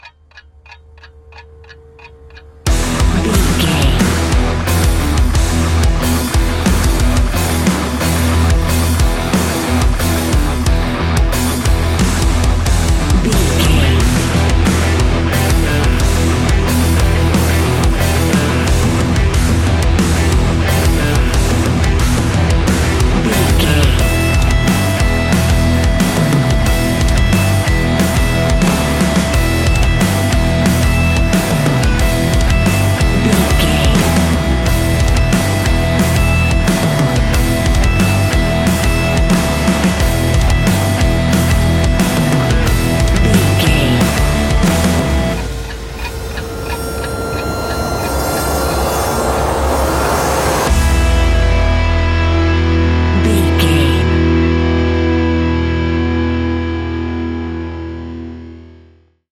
Ionian/Major
E♭
hard rock
instrumentals